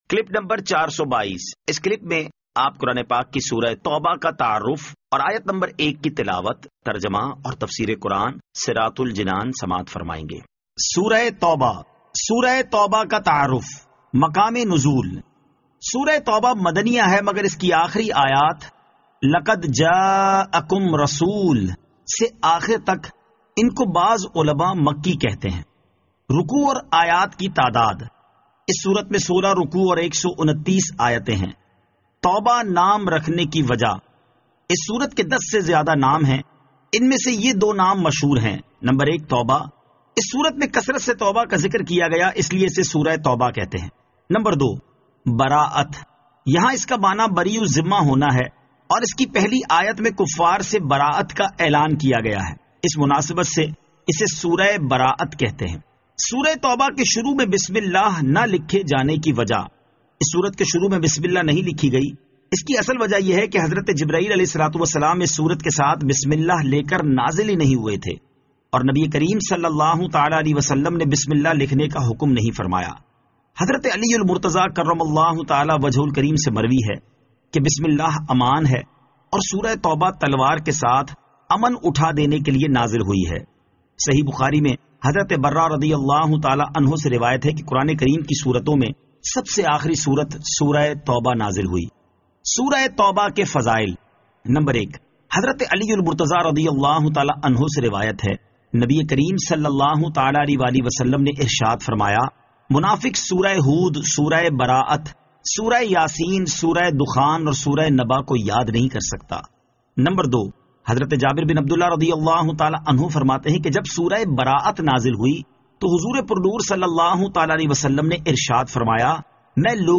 Surah At-Tawbah Ayat 01 To 01 Tilawat , Tarjama , Tafseer